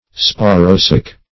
Sporosac \Spo"ro*sac\ (sp[=o]"r[-o]*s[a^]k), n. [Spore + sac.]